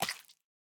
sounds / mob / frog / eat3.ogg
eat3.ogg